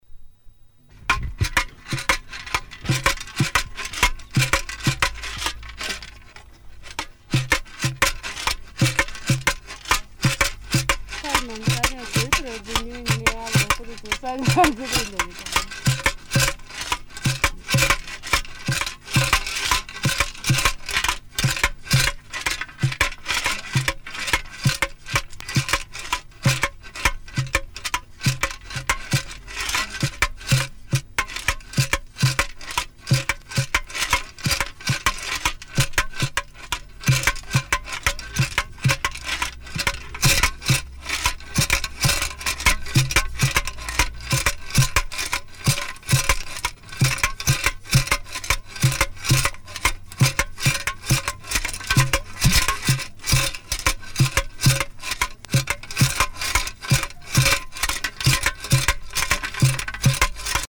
The yondo is an idiophone that is shaken or beaten.
During play, small stones are also put into the open pipe of the instrument and these create a constant rattling as the instrument is moved about.
The open ends of the pipe are beaten against the palm of the hand and against the player's calf successively. Another way is to strike the silver rings worn on the right wrist against the body of the yondo. This produces a different tone.
This is the only type of rattle found in Tibesti.